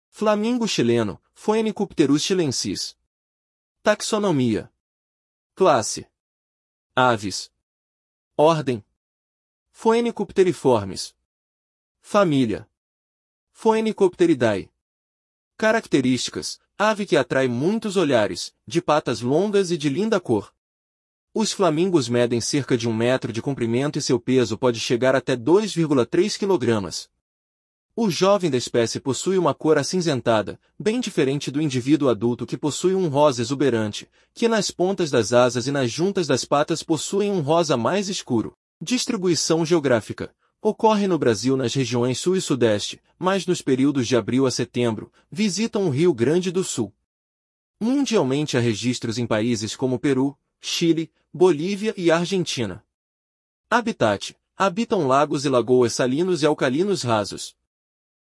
Flamingo-chileno (Phoenicopterus chilensis)